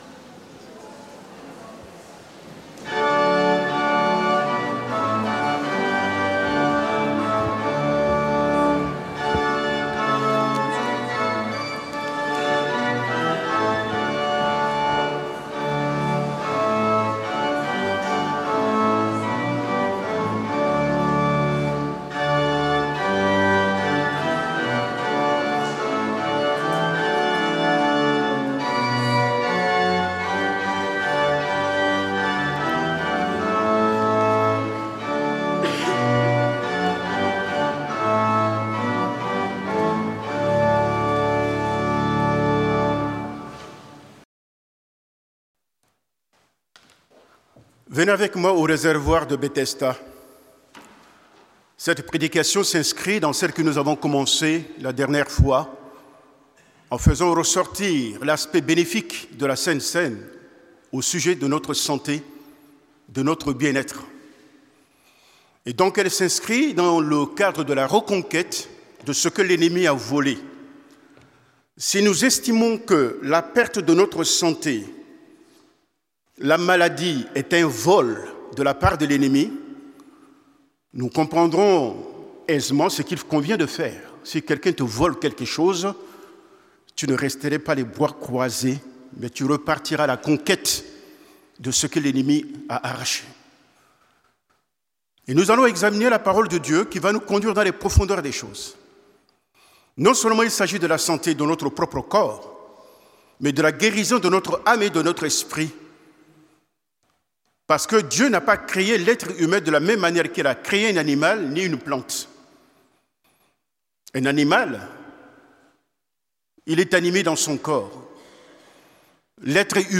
Prédication du dimanche 01 décembre 2024.